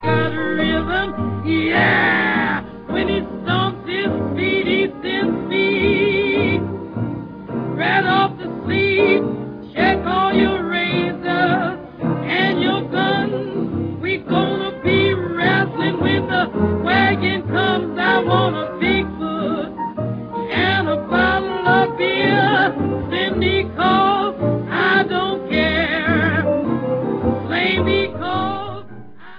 громоподобный